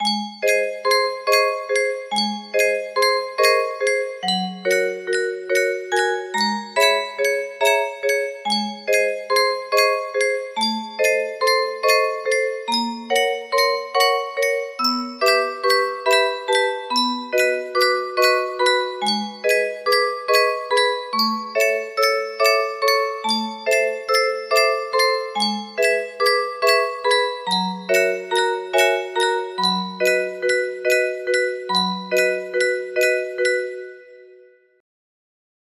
rain music box melody
Full range 60